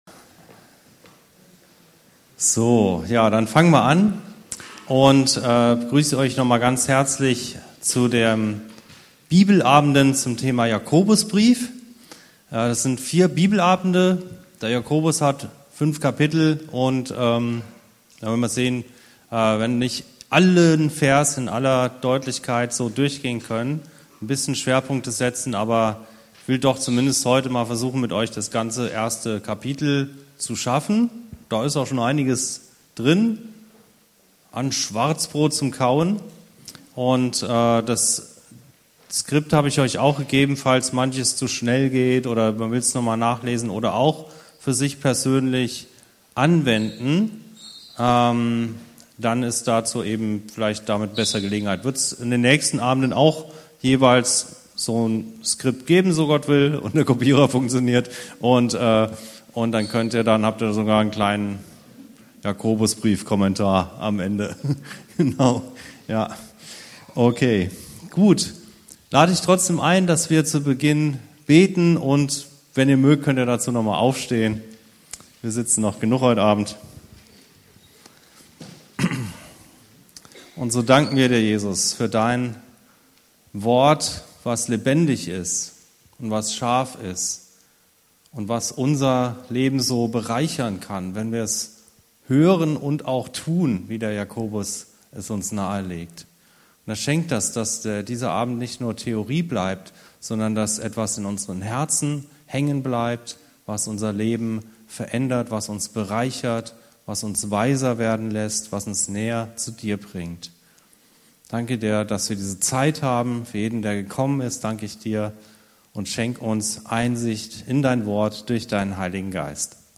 Predigten und Lehre aus der Anskar-Kirche Hamburg-Mitte